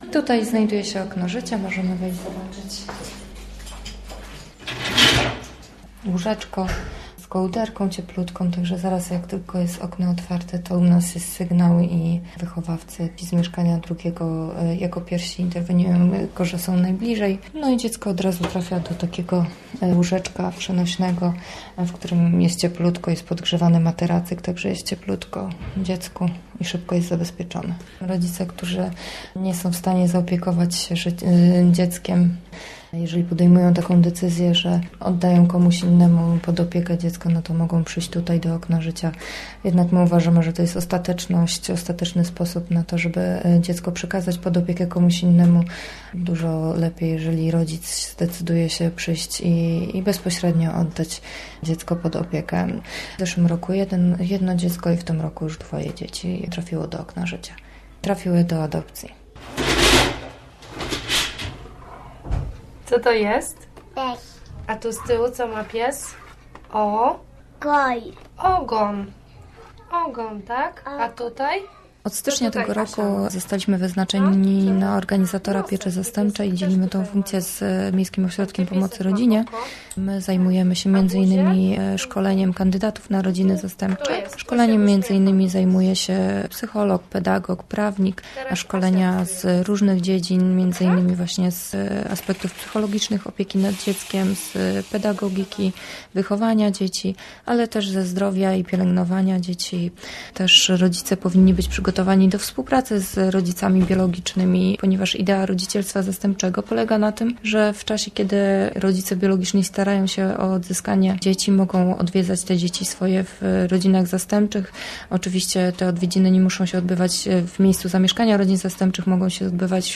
Ciocia cię uratuje - reportaż